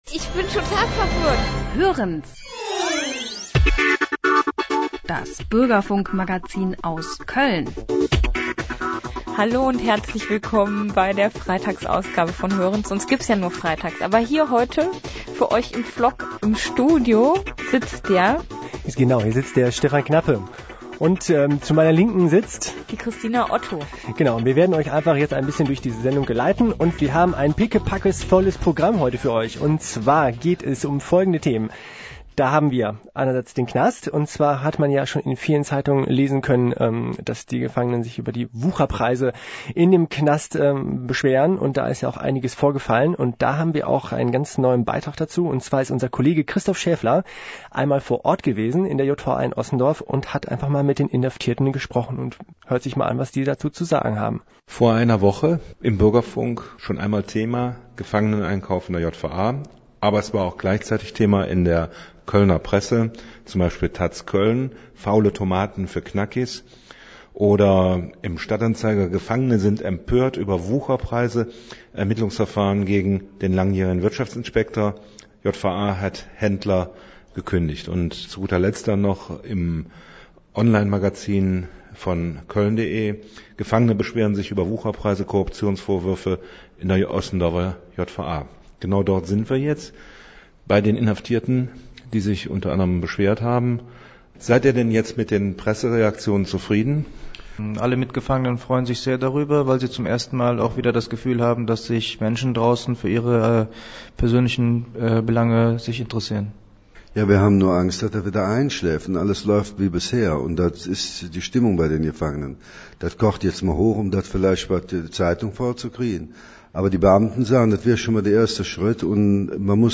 Als Beispiel hierzu diente die Berichterstattung über den neuen Knast-Skandal, bei dem Korruptions- und Betrugsvorwürfe zu Tage getreten sind. Die betroffenen Inhaftierten schildern im Bürgerfunk in einem sehr einseitigen, auch emotional besetzten Beitrag, Ihre Sicht der Dinge.